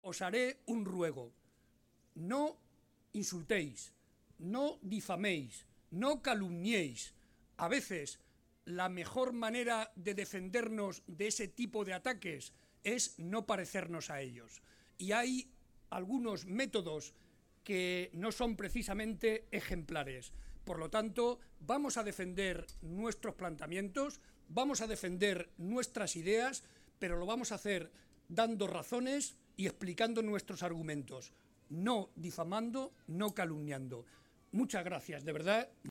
El secretario regional del PSOE y presidente de C-LM, José María Barreda, asistió a la presentación de la plataforma “Vecino a Vecino”
Cortes de audio de la rueda de prensa